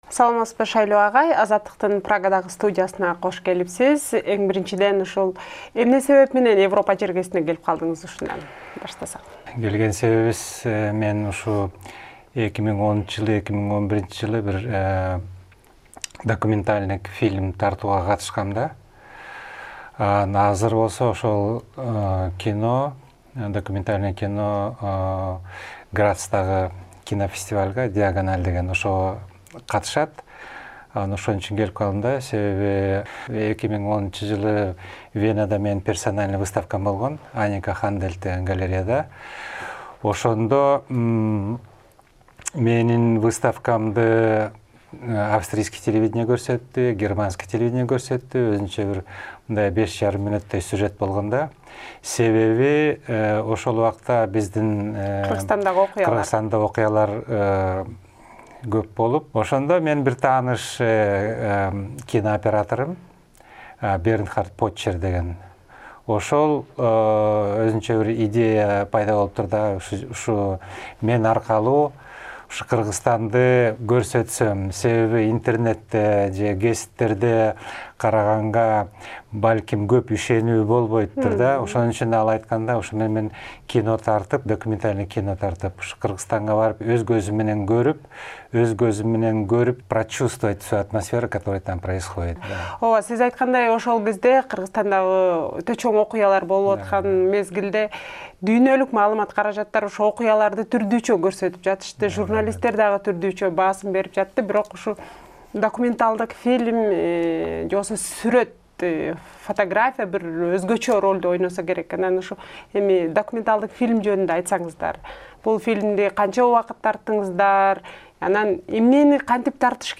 Прагадагы студиясында